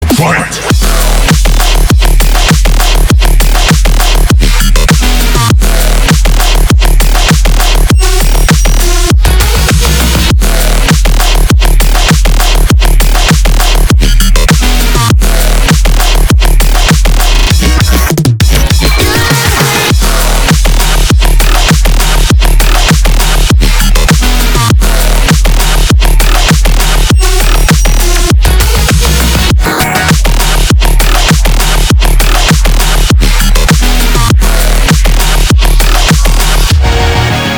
• Качество: 320, Stereo
громкие
жесткие
мощные
Electronic
Крутой дабстеп